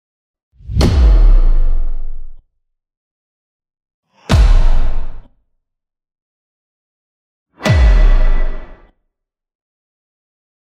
Âm thanh Chuyển Phụ Đề vang dội mạnh mẽ
Thể loại: Âm thanh chuyển cảnh
Description: Âm thanh Chuyển Phụ Đề vang dội mạnh mẽ là âm thanh chuyển cảnh dứt khoát, thu hút sự tập trung, chú ý của người xem, âm thanh phụ đề dứt khoát, mạnh mẽ với âm lượng lớn giúp người xem nắm bắt được kịp thời.
am-thanh-chuyen-phu-de-vang-doi-manh-me-www_tiengdong_com.mp3